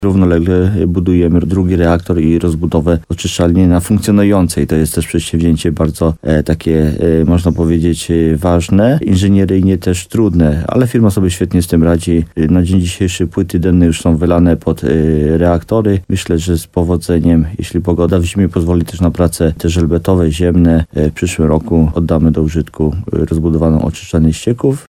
Jak powiedział w programie Słowo za Słowo w radiu RDN Nowy Sącz wójt Jacek Migacz, prace są skomplikowane, ale nie ma niespodzianek, które mogłyby powodować opóźnienie ich zakończenia.